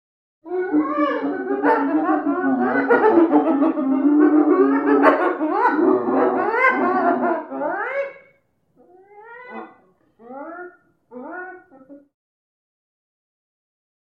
На этой странице собраны разнообразные звуки гиены: от характерного смеха до агрессивного рычания.
Группа пятнистых гиен хохочет